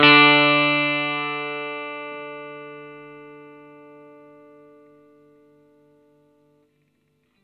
R12 NOTE  D.wav